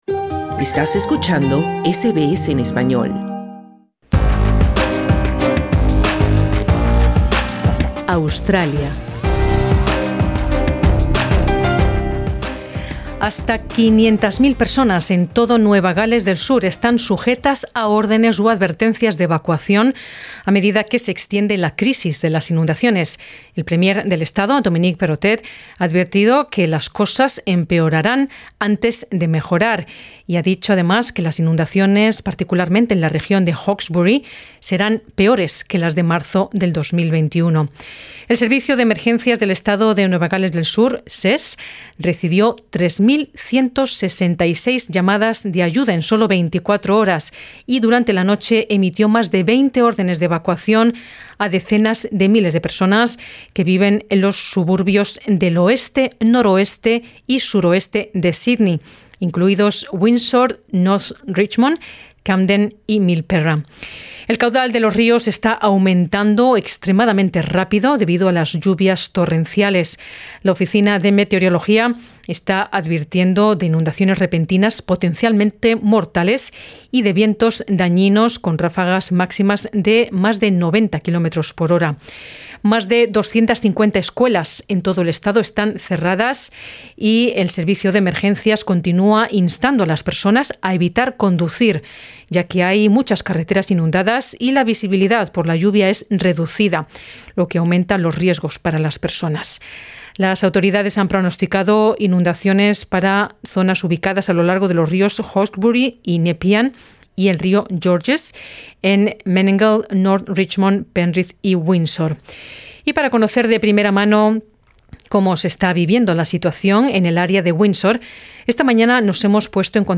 Los residentes de las áreas de Lismore y la cuenca del río Hawkesbury están sufriendo los efectos más perniciosos de las inundaciones de Nueva Gales del Sur. Algunos de ellos relatan para SBS Spanish el nerviosismo y la devastación con que viven una catástrofe que ha resultado ser peor de lo esperado.